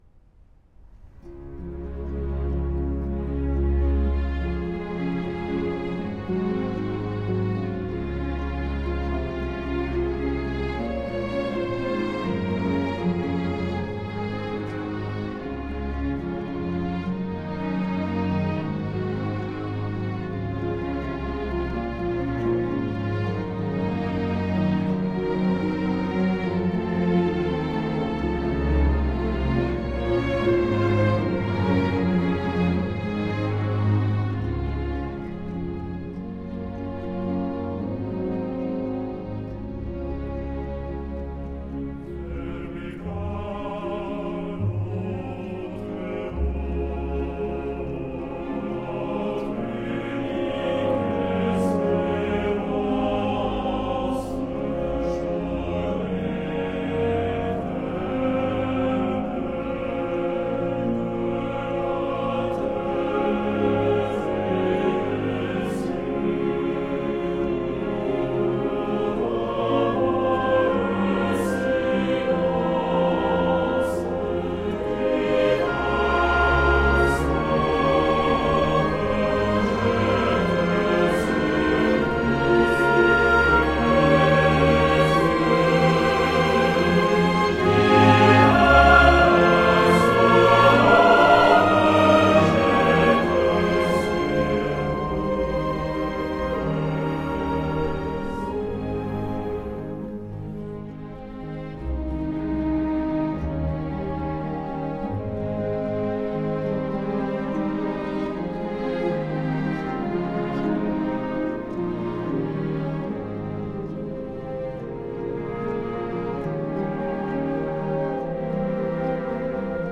Recorded 2008
soprano
baritone
solo violin
organ
orchestral version